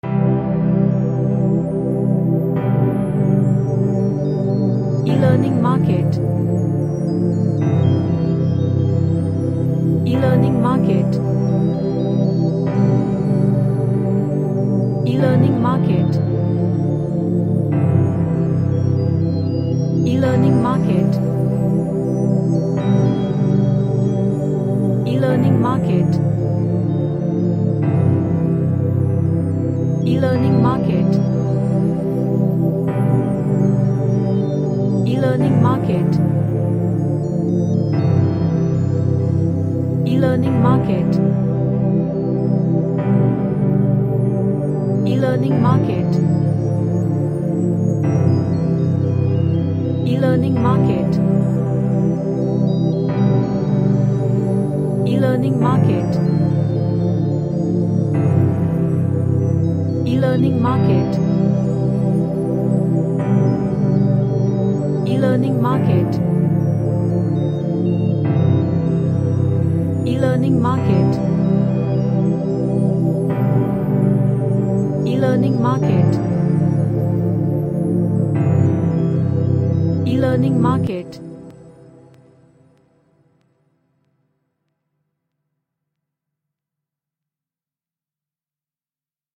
An ambient track with pads and sine melody.
Relaxation / Meditation